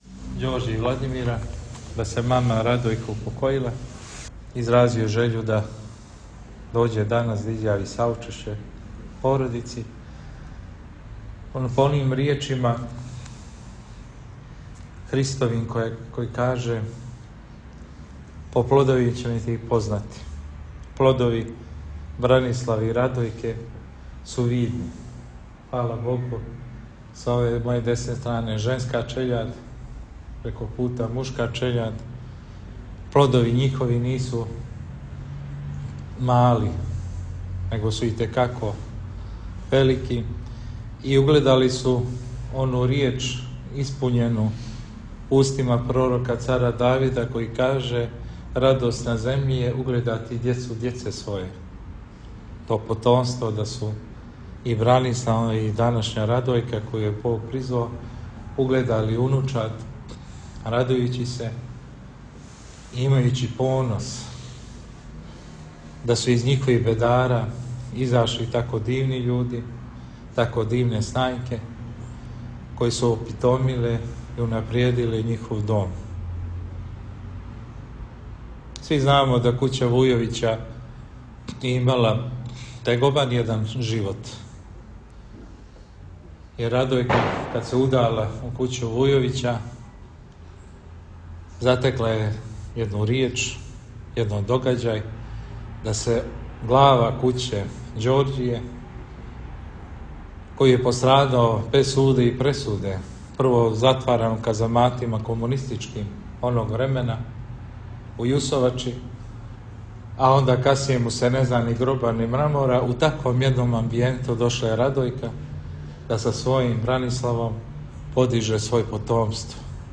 Бесједа